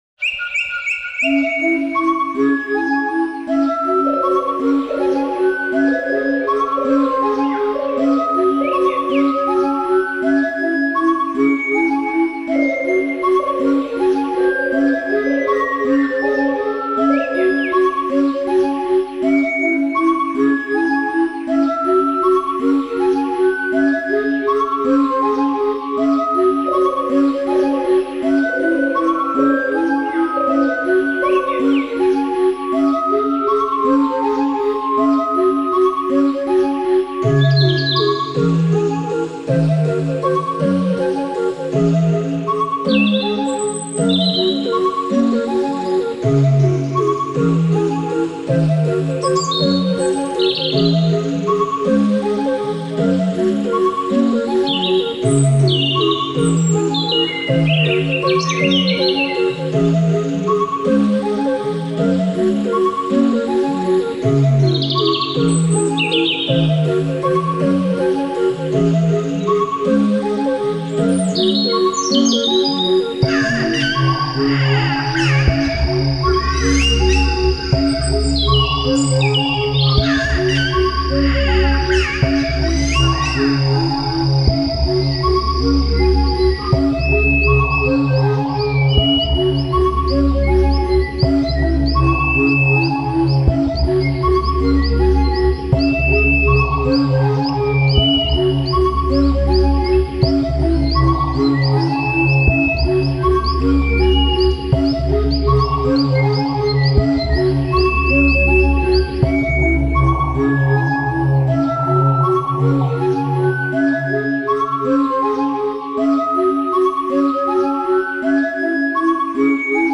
Ambiance sonore : Dans la canopée
canopee.mp3